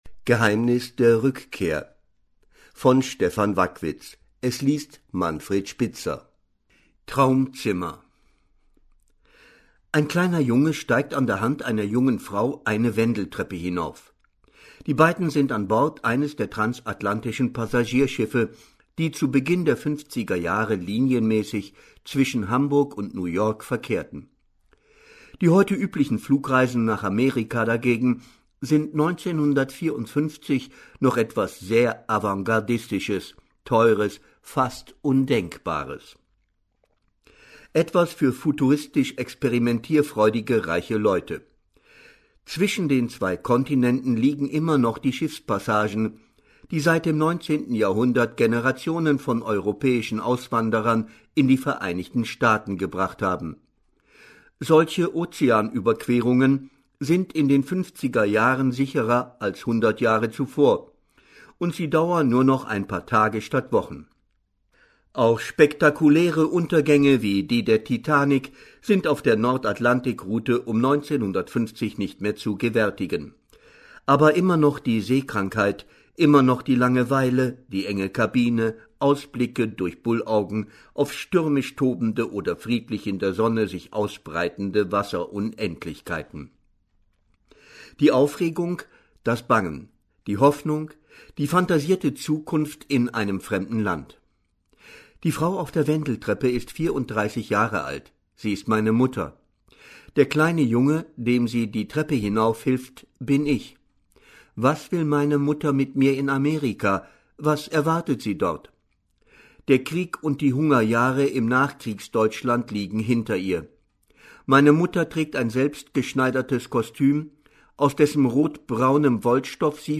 Manfred Spitzer liest diesen außergwöhnlichen Blick auf die Welt und unser Land: